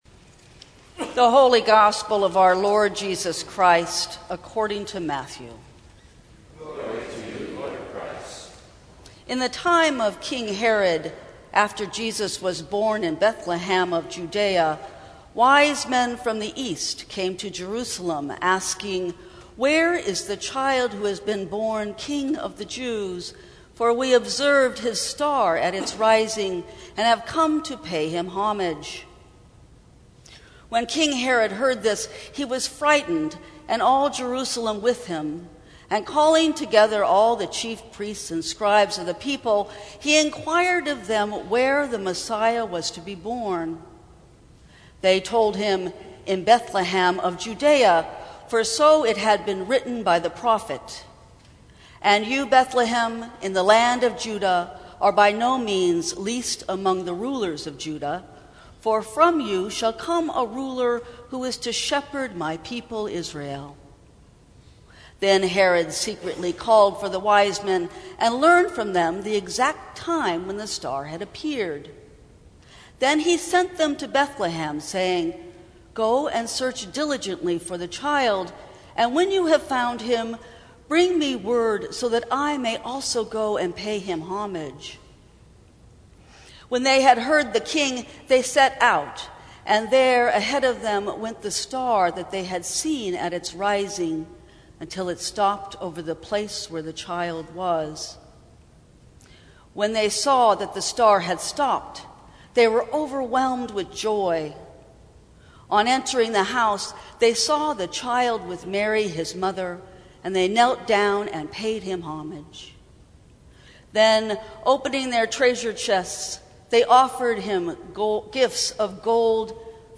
Sermons from St. Cross Episcopal Church Where is Jesus?